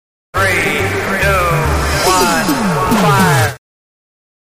Kermis geluid 321 Fire
Categorie: Geluidseffecten
Dit geluid brengt de explosieve sfeer van de kermis naar je toe.
geluidseffecten, kermis geluiden
kermis-geluid-3-21-fire-nl-www_tiengdong_com.mp3